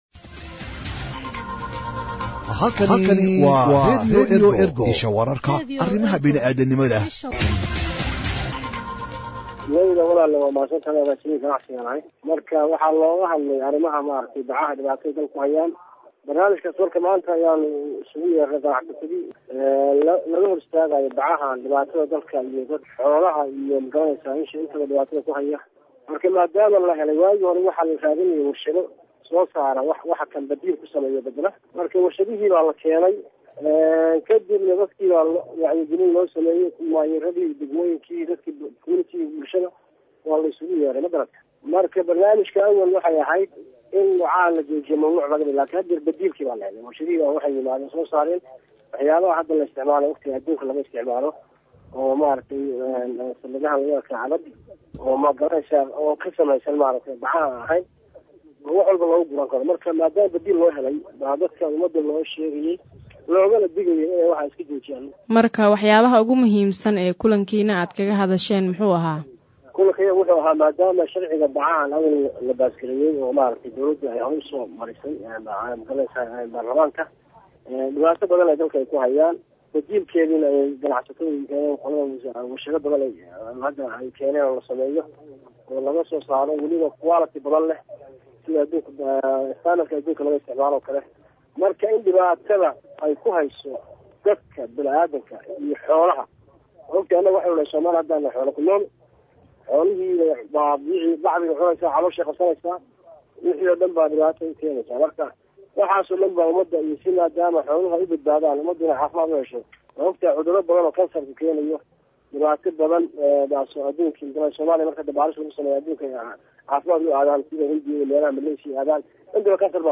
Wuxuu tilmaamay in qofki lagu qabto isagoo bac iibinaya ama la soo degaya la ganaaxi doono. Agaasimaha ayaa taleefanka ugu warramay